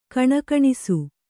♪ kaṇakaṇisu